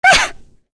Lewsia_A-Vox_Damage_05.wav